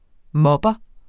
Udtale [ ˈmʌbʌ ]